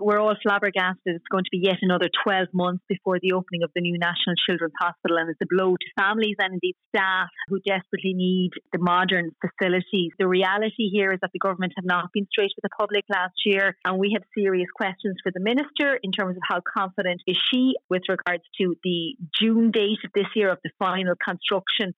It’s health spokesperson Marie Sherlock says its not good enough.